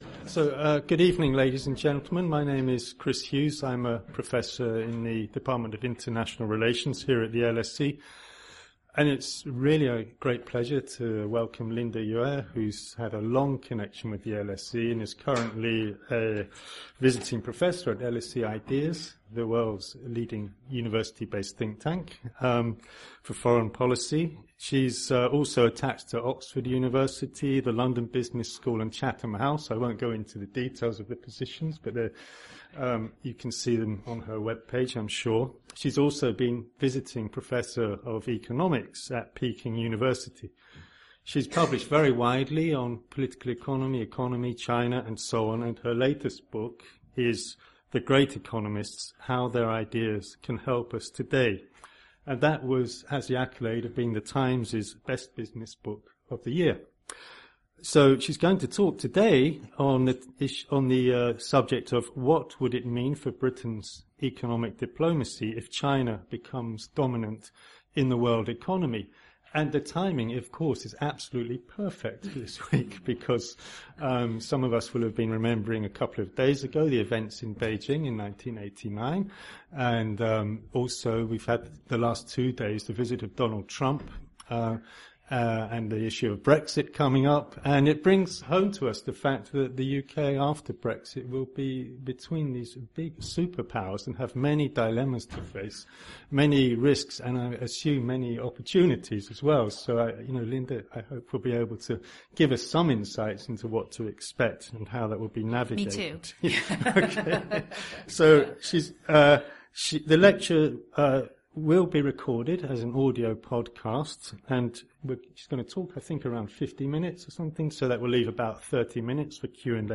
Listen to the podcast: UK-China economic diplomacy Download the podcast: UK-China economic diplomacy Event recorded 6 June 2019.